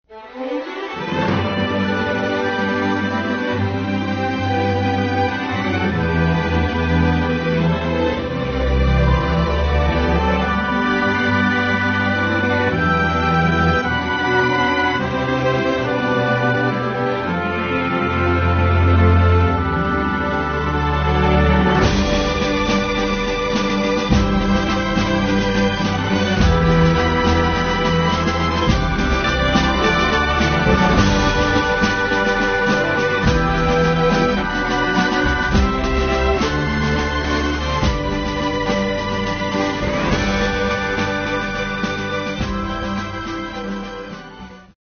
6月28日，吉林省税务局举行升国旗暨重温入党誓词活动。
升国旗仪式后，在场人员郑重举起右拳，在吉林省税务局党委书记刘虎带领下，重温入党誓词。铿锵有力的誓词，激发起党员干部在回望党的光辉历程中奋进新征程的昂扬斗志。